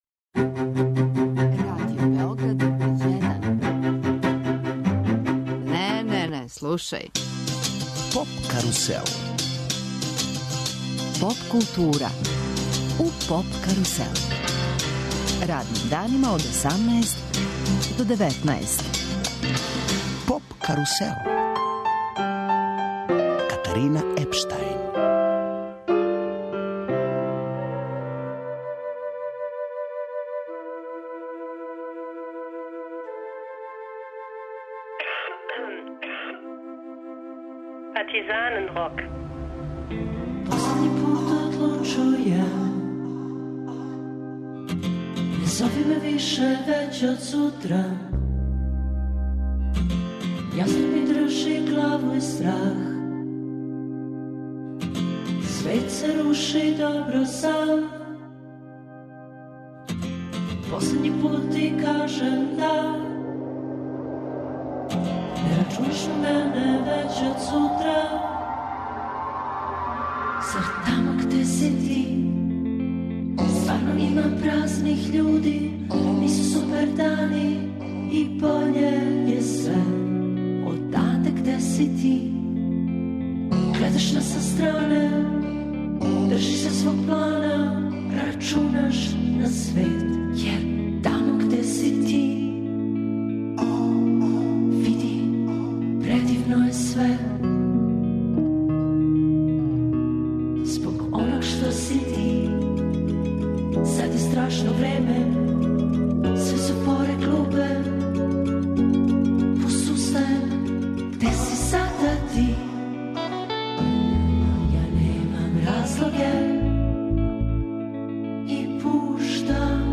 Представљамо вам избор Радио Београда 1, најлепших домаћих песама које смо емитовали у 2013. години.